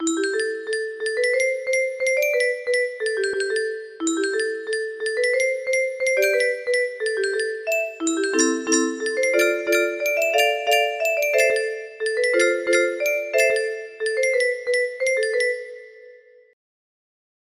Solo then triplet, then solo